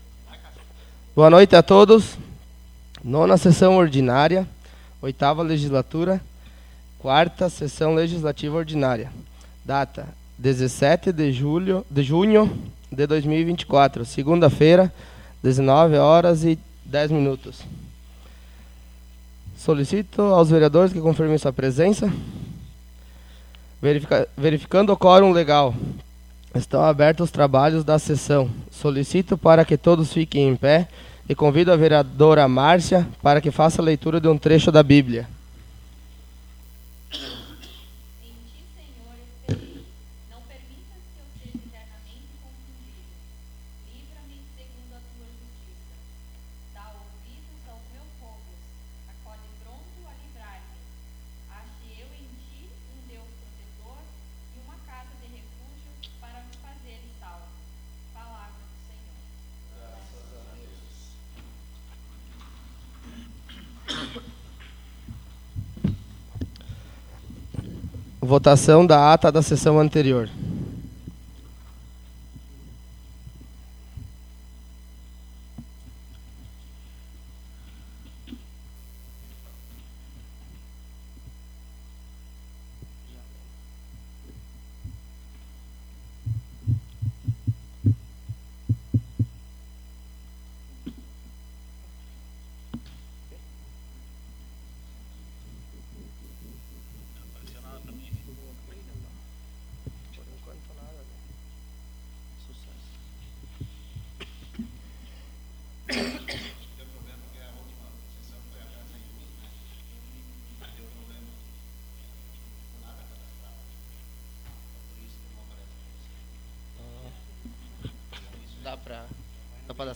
Audio da 9ª Sessão Ordinária 17.06.24